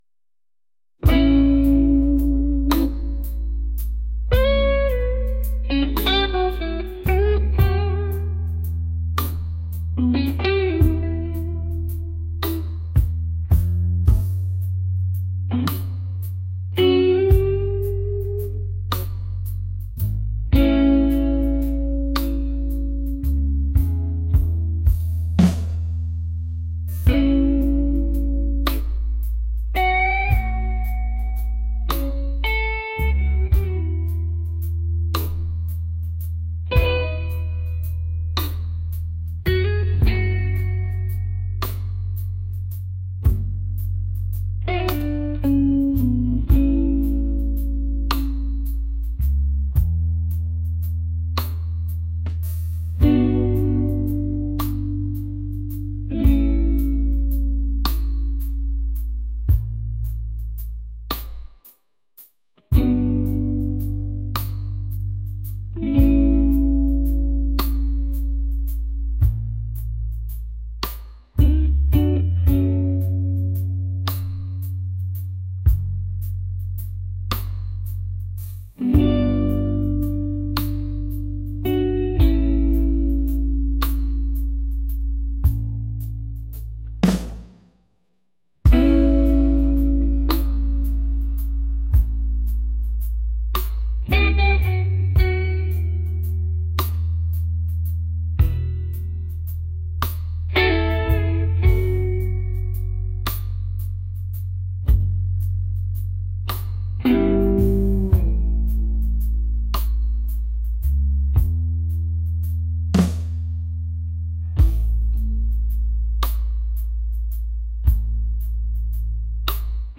soulful | blues